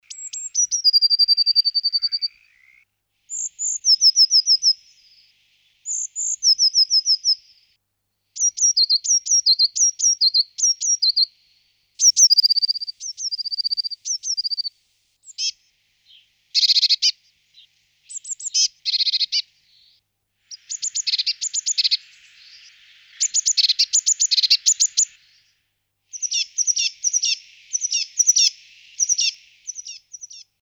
Orto botanico - Cinciarella
cinciarella.mp3